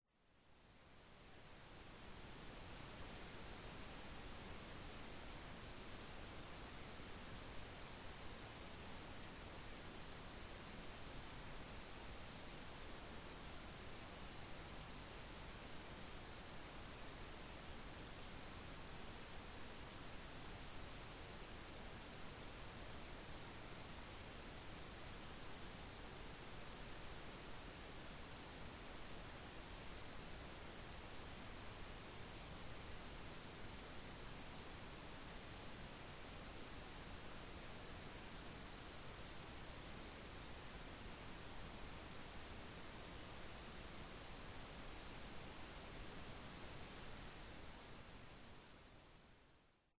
Calming nature recordings and ambient soundscapes.
Night Crickets
Duration: 0:50 · Type: Nature Recording · 128kbps MP3
Night_Crickets.mp3